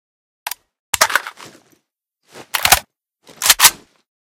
reload_empty.ogg